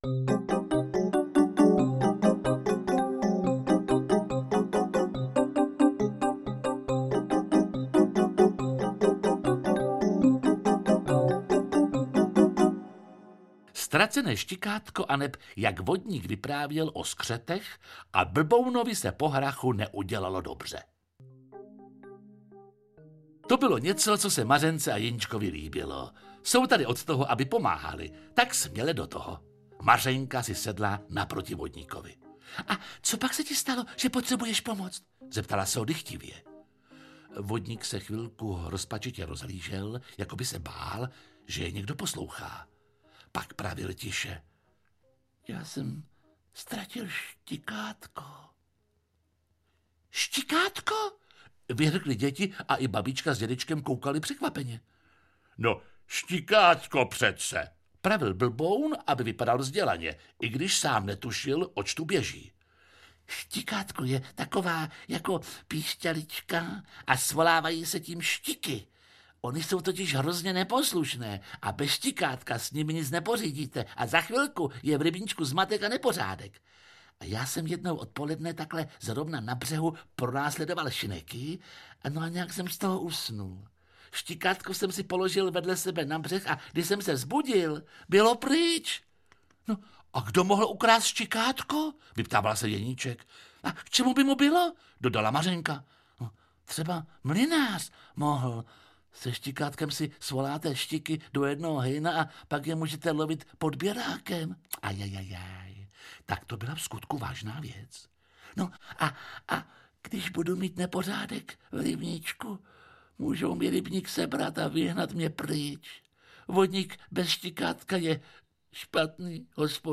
S Blbounem do říše pohádek audiokniha
Ukázka z knihy
• InterpretJiří Lábus